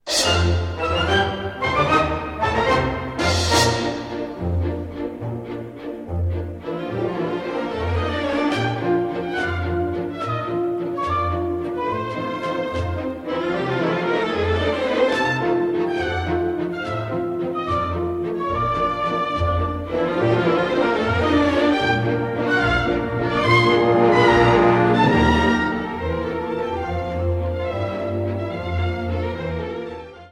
вальсы